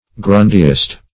Meaning of grundyist. grundyist synonyms, pronunciation, spelling and more from Free Dictionary.
Search Result for " grundyist" : The Collaborative International Dictionary of English v.0.48: Grundyism \Grun"dy*ism\, n. Narrow and unintelligent conventionalism.